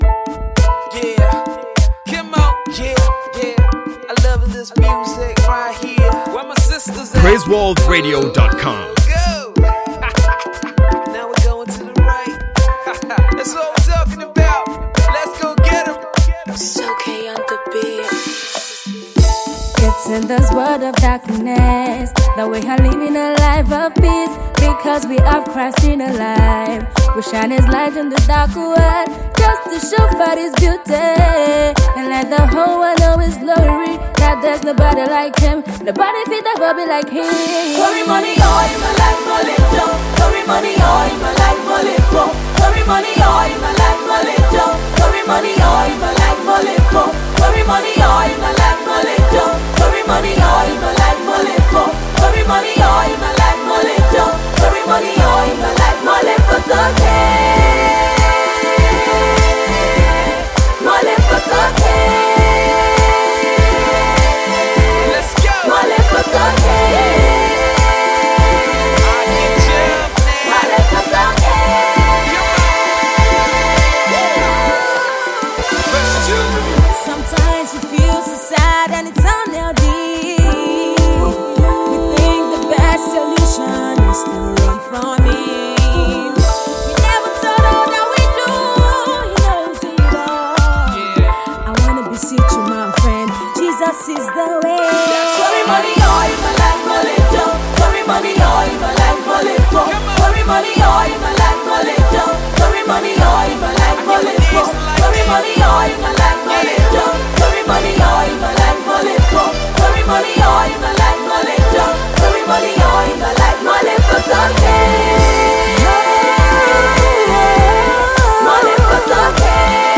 Fast rising Nigerian quartet
The group consists of four beautiful young ladies
a song of encouragement